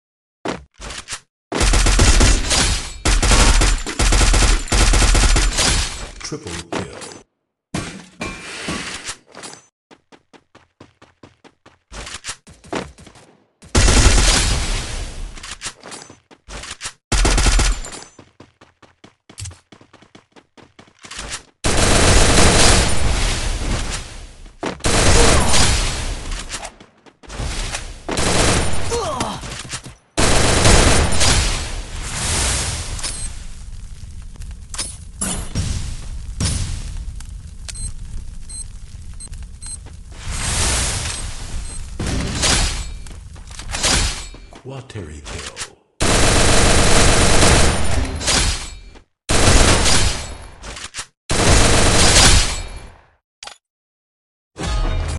XM8 + MP40 on max level gameplay